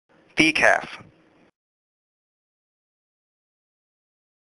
這才是道地的美式發音喔！
重音要擺在第一個音節。
“ei”只發 / I /。